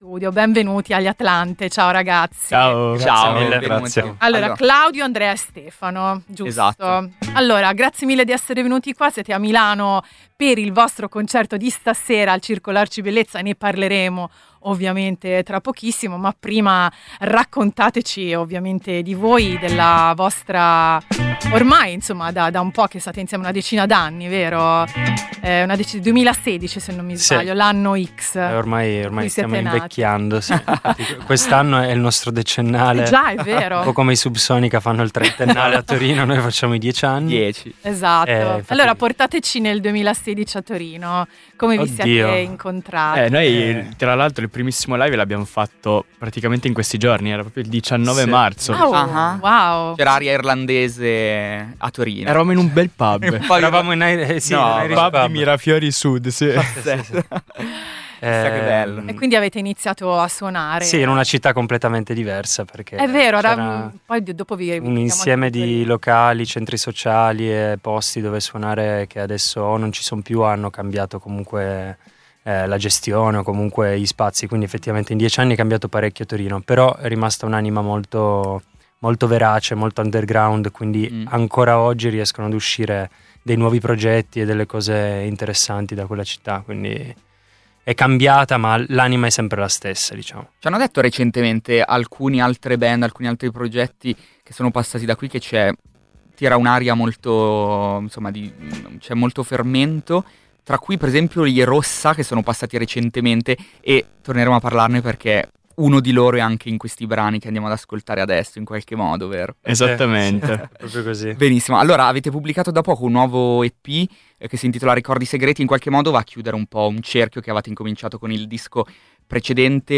Gli Atlante e quella voglia di reinventarsi a ogni album: la band torinese intervistata a Volume | Radio Popolare